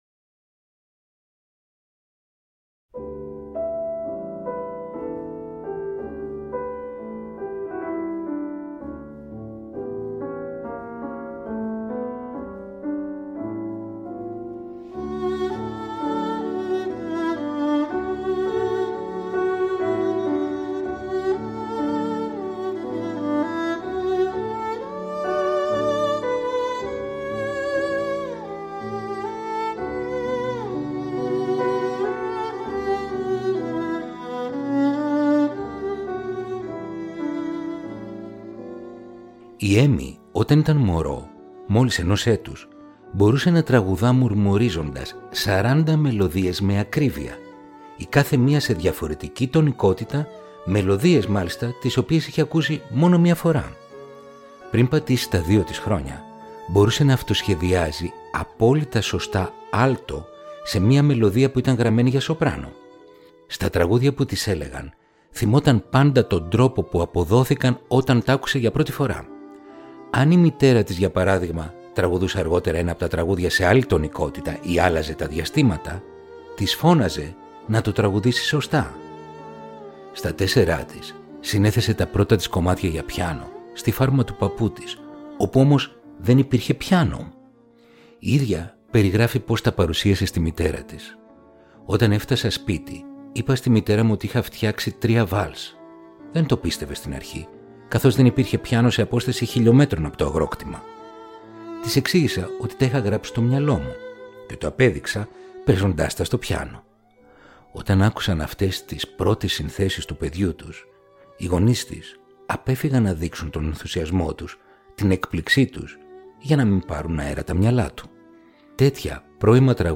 Ρομαντικά κοντσέρτα για πιάνο – Επεισόδιο 27ο